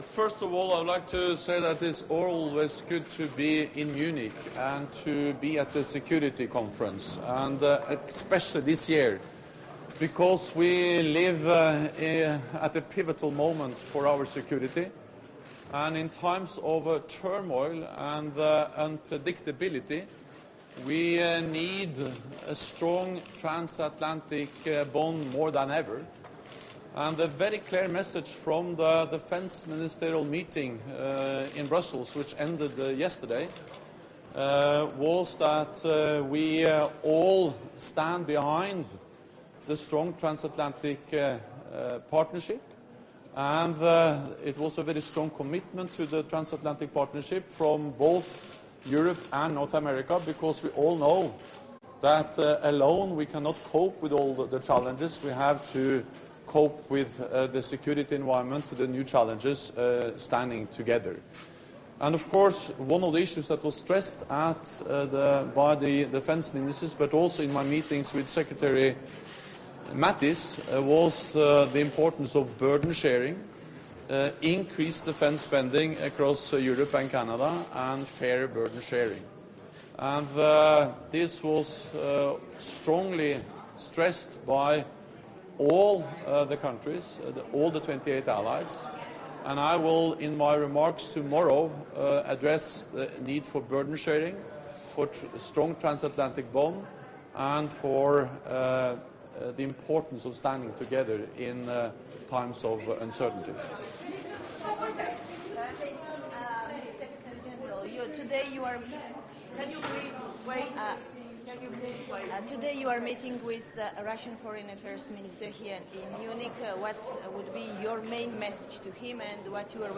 Doorstep statement by NATO Secretary General Jens Stoltenberg at the start of the Munich Security Conference
(As delivered)